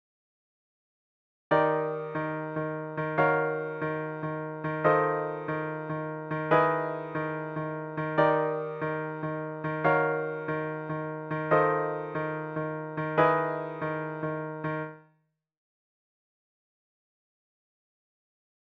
スウィング